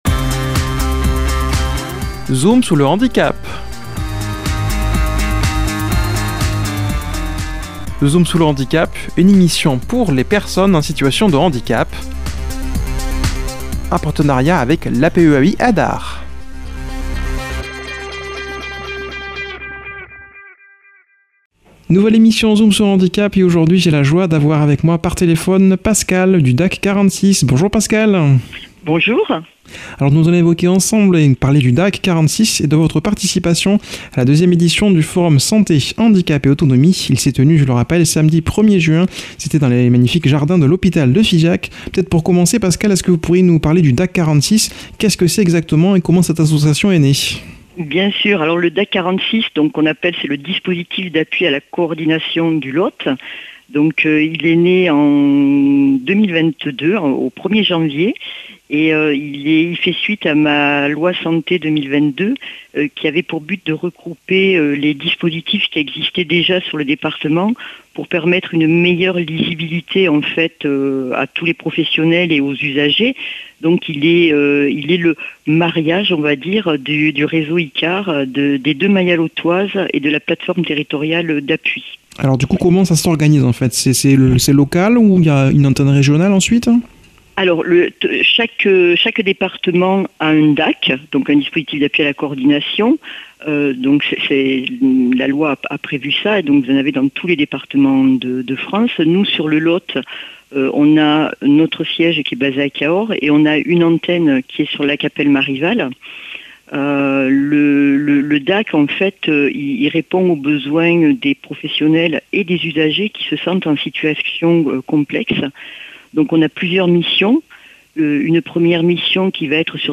a comme invitée par téléphone